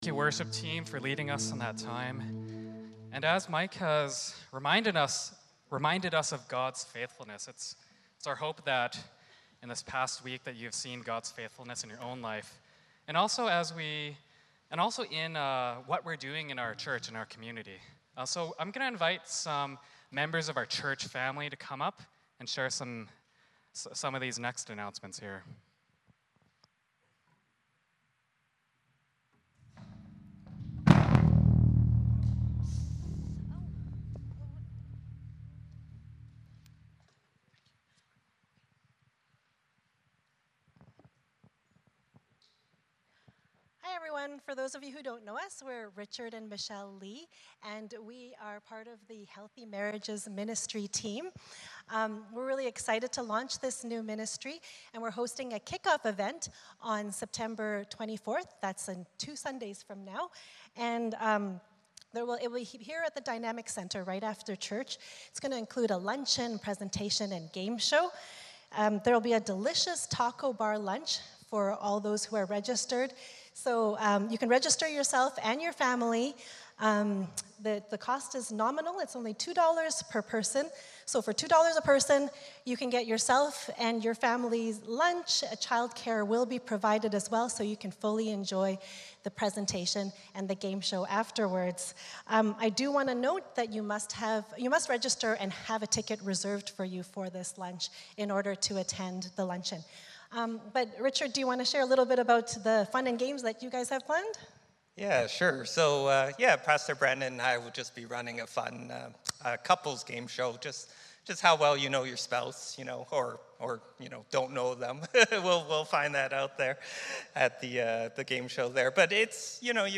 John 12:1-8 Service Type: Sunday Morning Service Passage